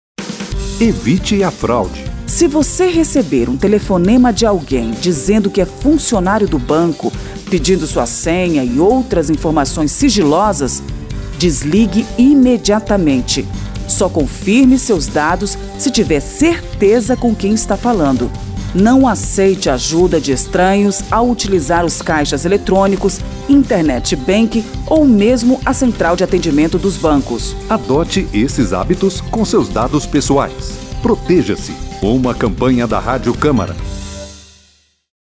spot-5-evite-a-fraude.mp3